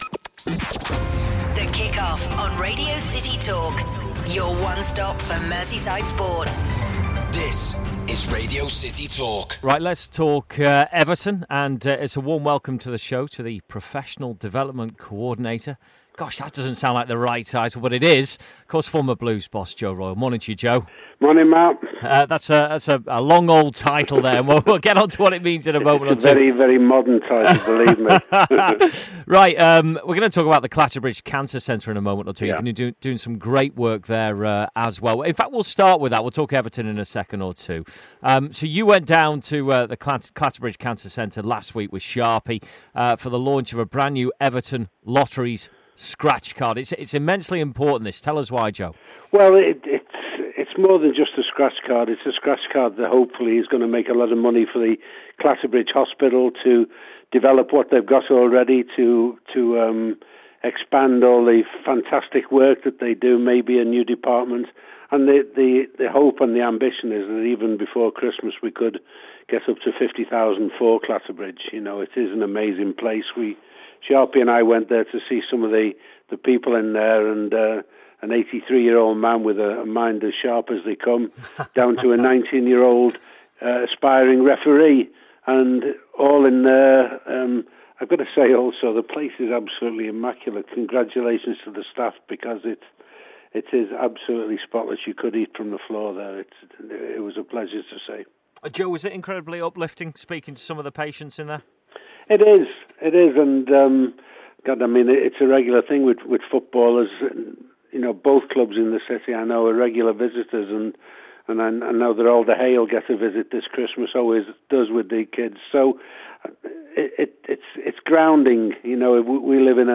Former Everton manager Joe Royle speaks